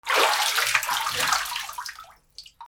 水から上がるときの水音 1